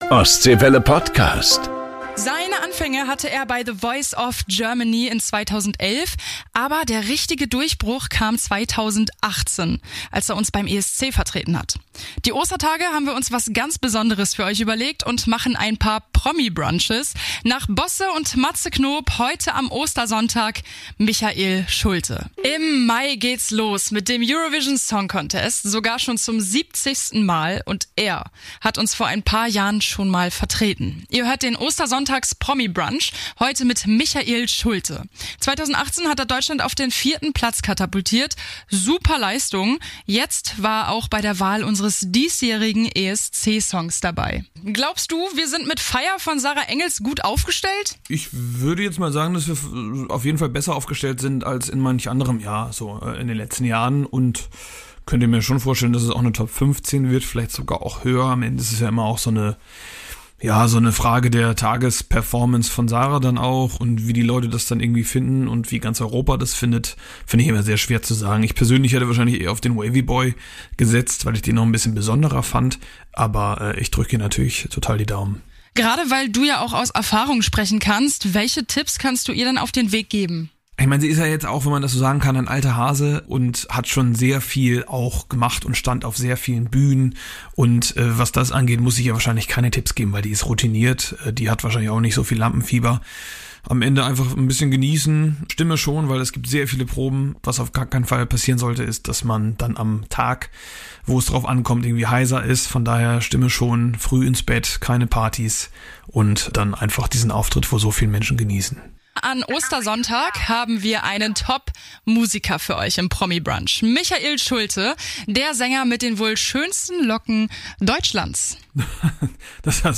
Im privaten Gespräch mit Ostseewelle-Musikstar Michael Schulte